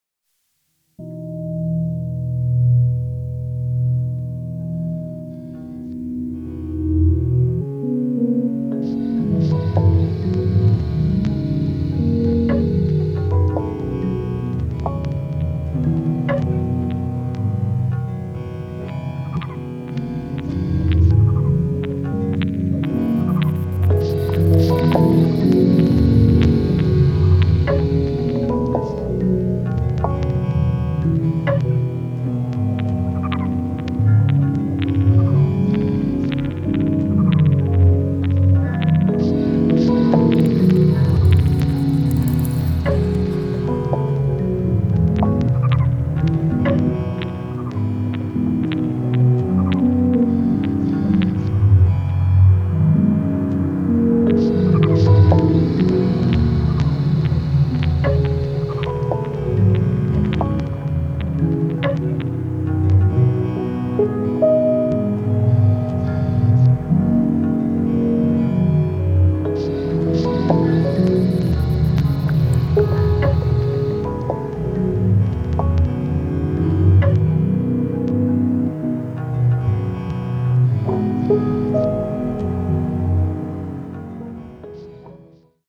Beautiful simple organic/ambient...
Ambient
Electroacoustic, Experimental